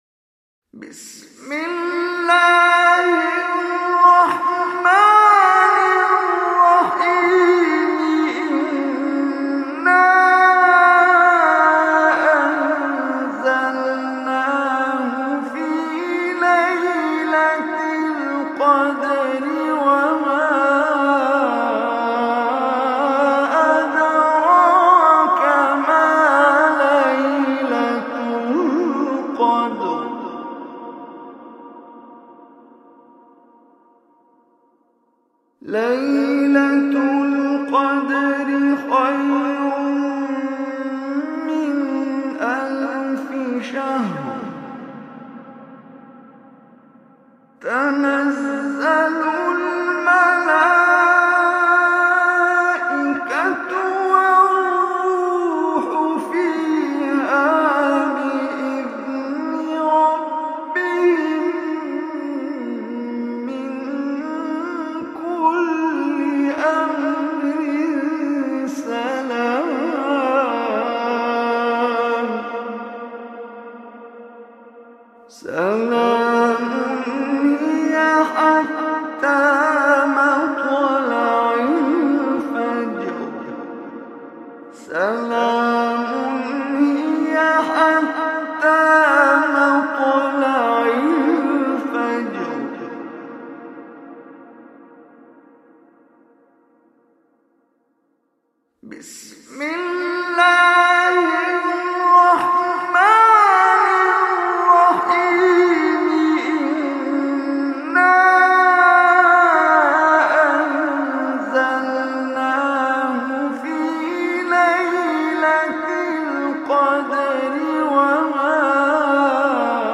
Surah Qadr MP3 Recitation by Omar Hisham Arabi
Surah Qadr, listen or play online mp3 tilawat / recitation in Arabic in the beautiful voice of Omar Hisham Al Arabi.